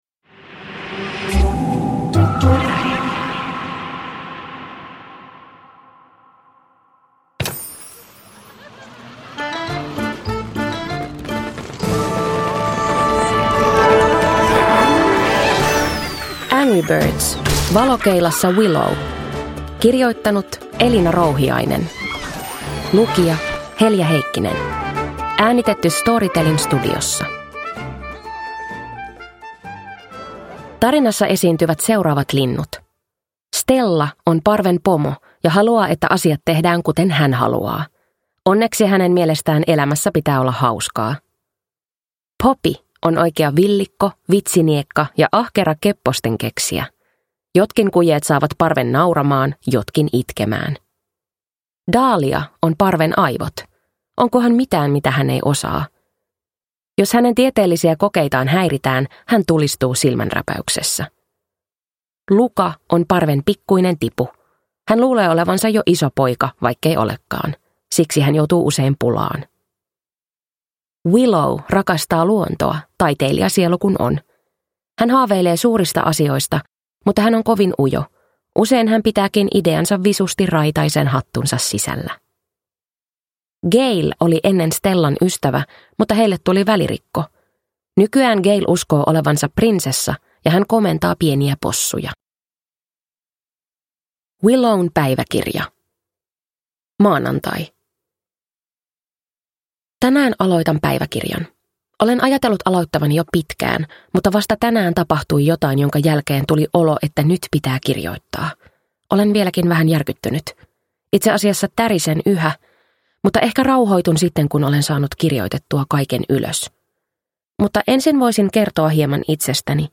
Angry Birds: Valokeilassa Willow – Ljudbok – Laddas ner